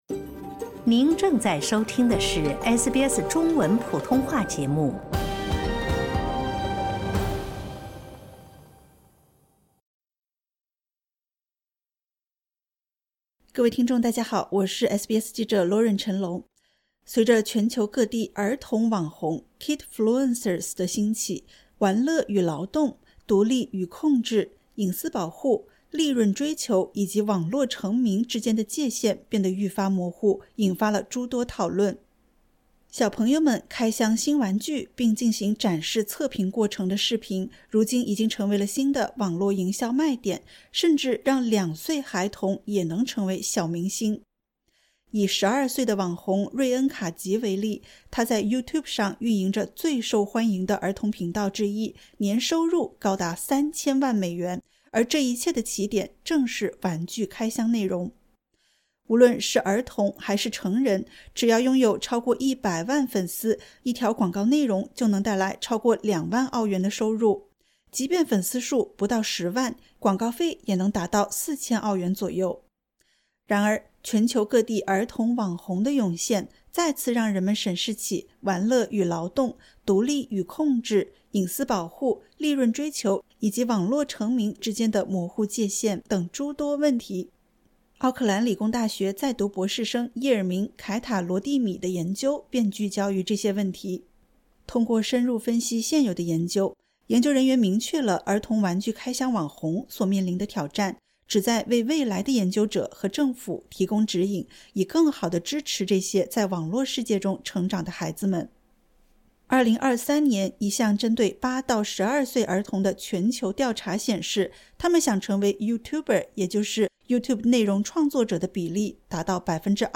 随着全球各地“儿童网红”（kidfluencers）的兴起，玩乐与劳动、独立与控制、隐私保护、利润追求以及网络成名之间的界限变得愈发模糊，引发诸多讨论。点击 ▶ 收听完整报道。